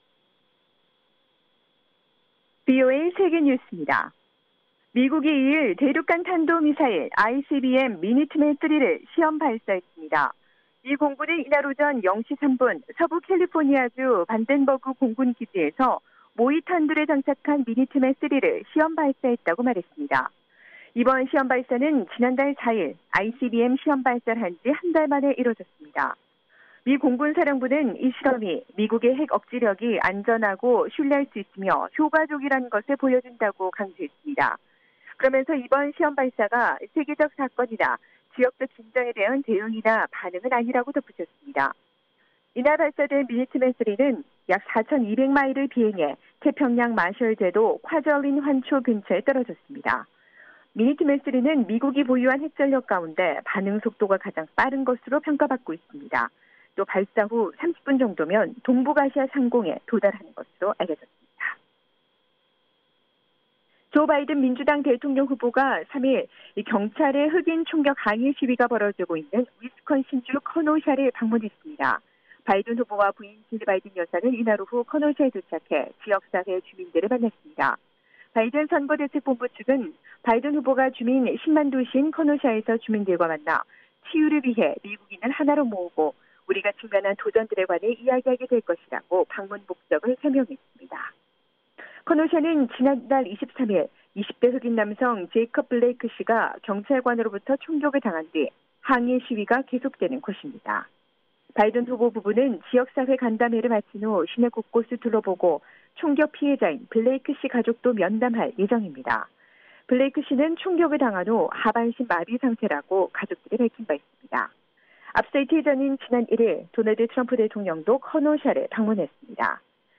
VOA 한국어 아침 뉴스 프로그램 '워싱턴 뉴스 광장' 2020년 9월 4일 방송입니다. 데이비드 스틸웰 미 국무부 차관보가 북한의 사이버 활동에 대한 강력한 증거가 있다고 말했습니다. 미 국방부 고위관리가 북한이 대륙간탄도미사일(ICBM) 역량 증진을 추진하고 있다고 밝혔습니다. 국제원자력기구(IAEA)는 북한이 지난 1년 동안 우라늄 농축 활동을 계속한 것으로 보인다고 분석했습니다.